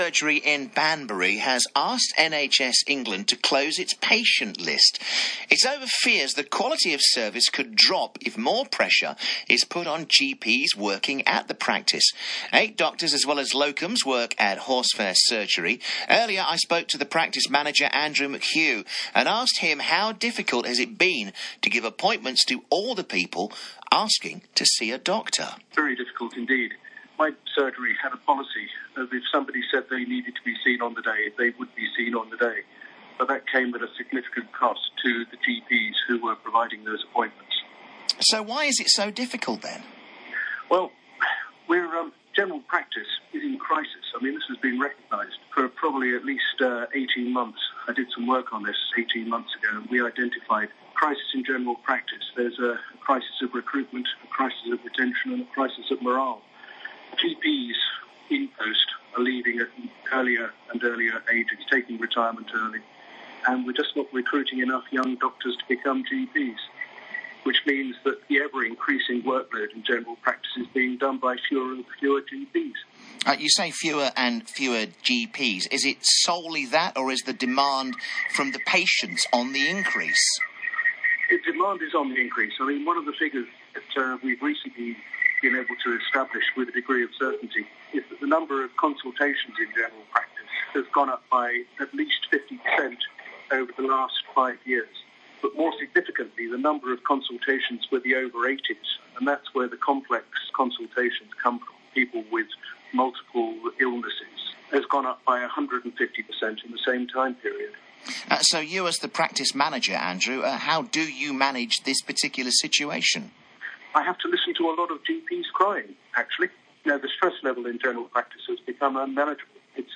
Interview with Radio OXFORD 14 January 2016